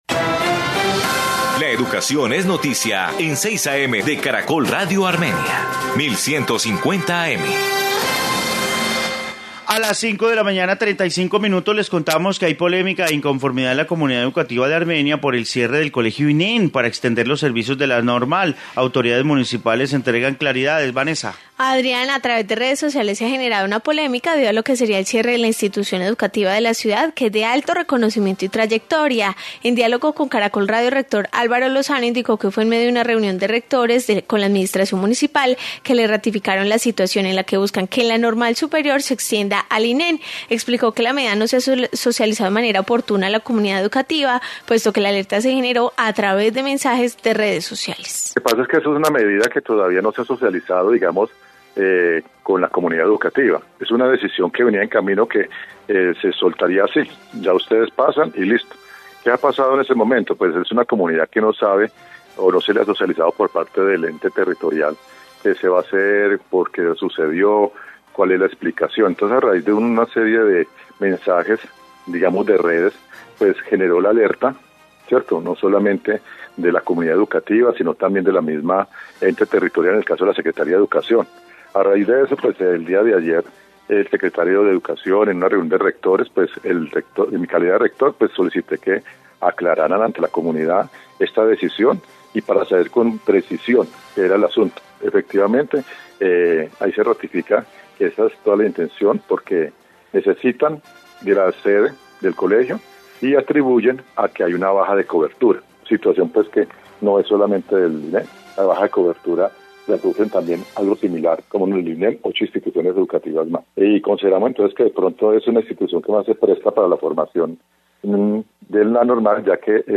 Informe caso del colegio Inem de Armenia